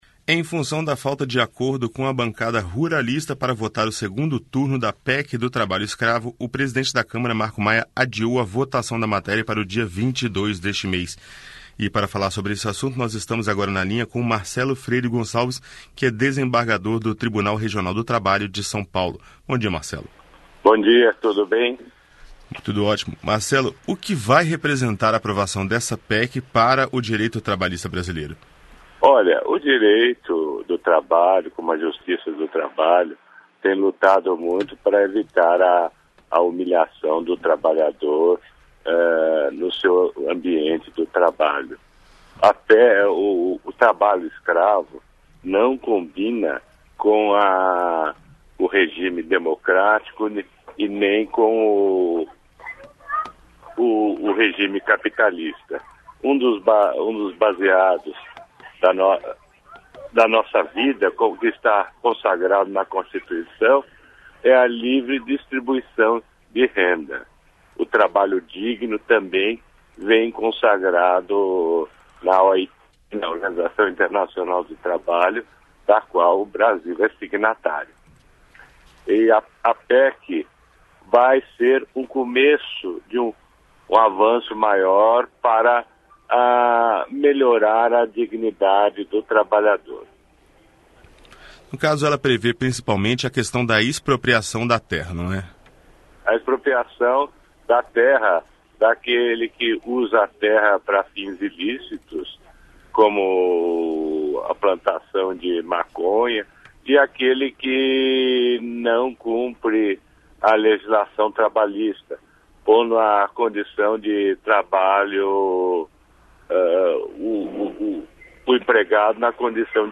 Câmara adia votação da PEC do Trabalho Escravo Entrevista com o desembargador do Tribunal Regional do Trabalho de SP, Marcelo Freire Gonçalves.